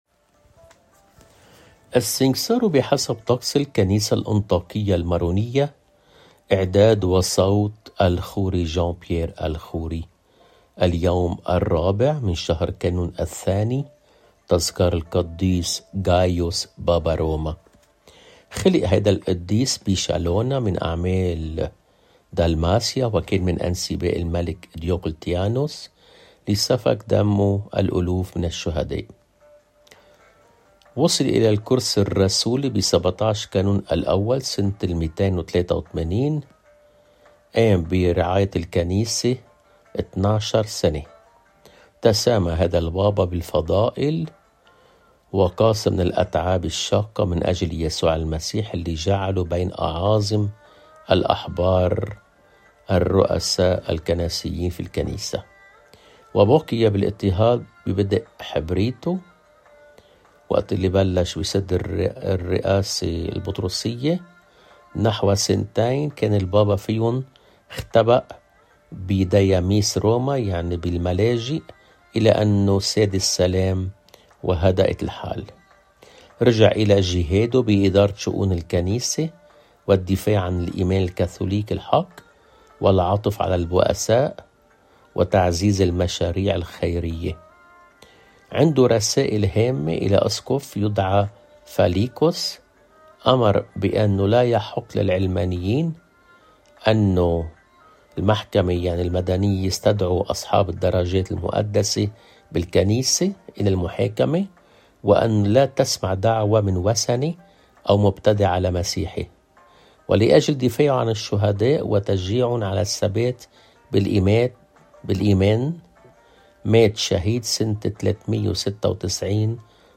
بصوت